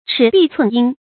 尺壁寸阴 chǐ bì cùn yīn
尺壁寸阴发音